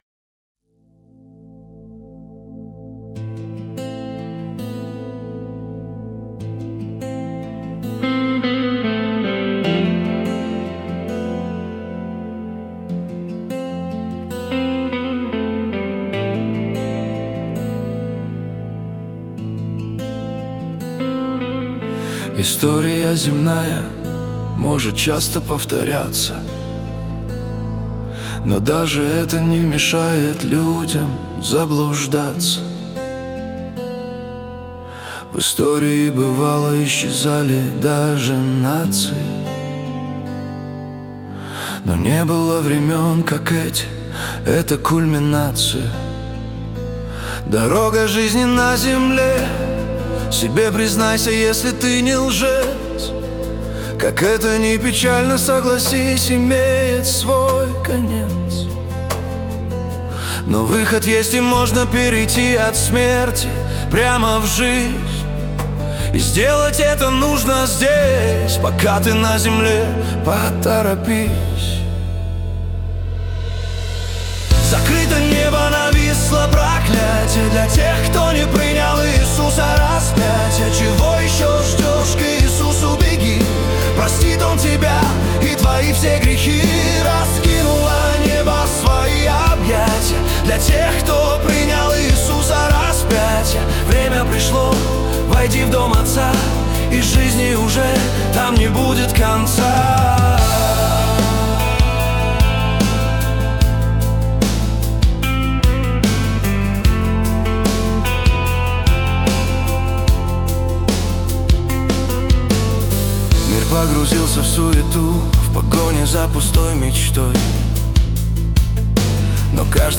песня ai
149 просмотров 701 прослушиваний 50 скачиваний BPM: 75